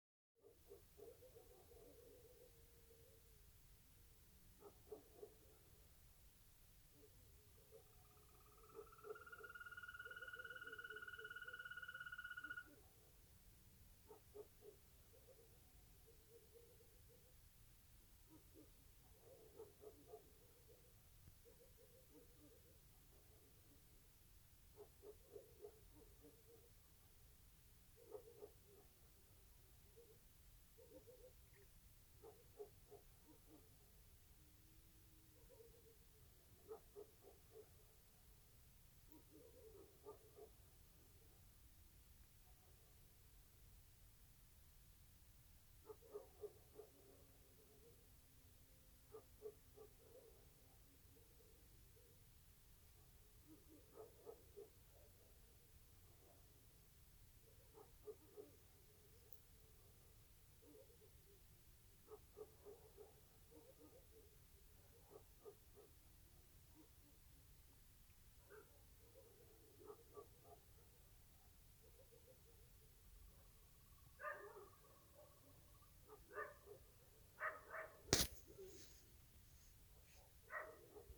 Ziņotāja saglabāts vietas nosaukumsLīču ciems
СтатусСлышен голос, крики
ПримечанияTas "kaut kas" dzied joprojām